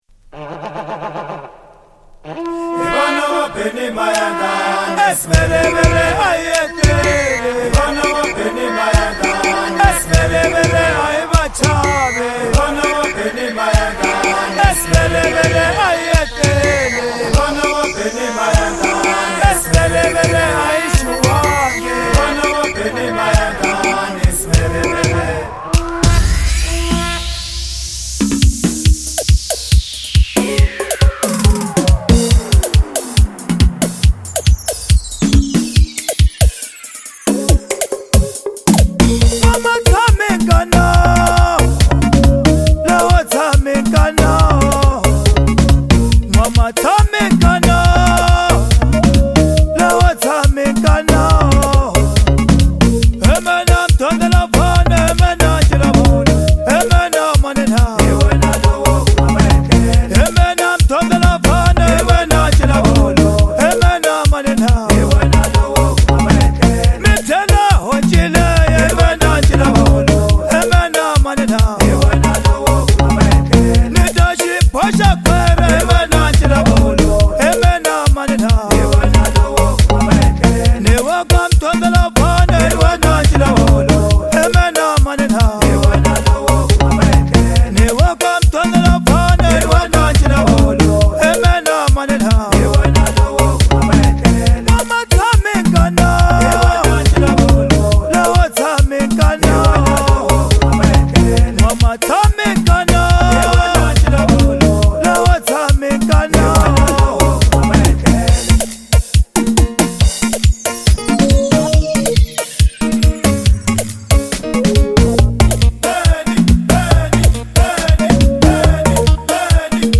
Foreign MusicSouth African
With its infectious rhythms and soulful vocals